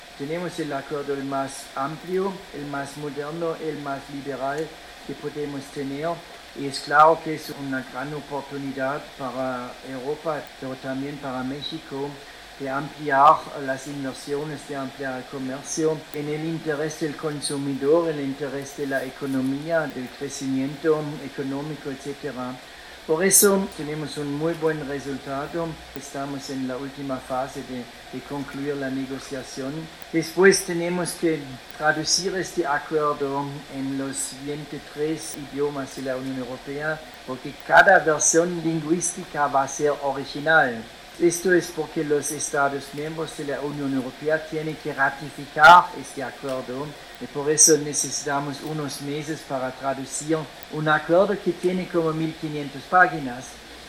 Al dictar la conferencia magistral “El estado actual que guarda la modernización del TLCUEM”, el diplomático remarcó que se trata del acuerdo más completo y ambicioso que la UE tiene con un país, porque además incluye principios democráticos, derechos y libertades fundamentales, así como el compromiso con el desarrollo sustentable.